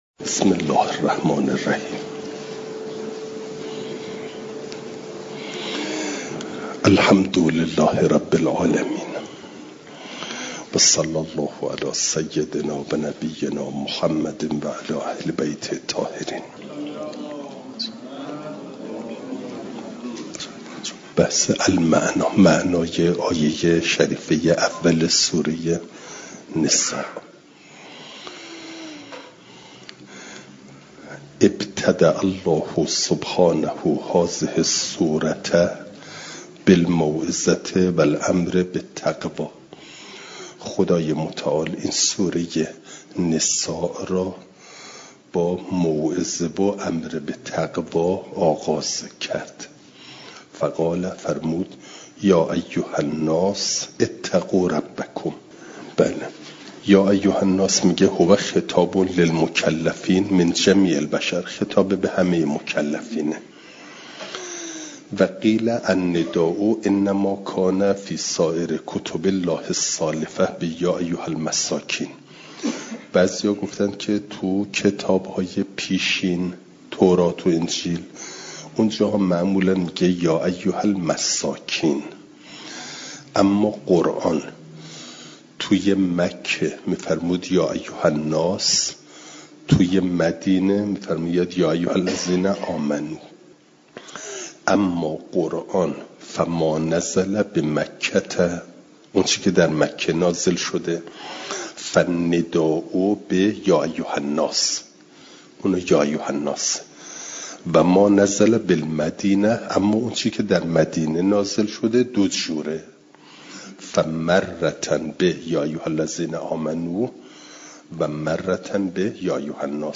جلسه سیصد و سی و هفتم درس تفسیر مجمع البیان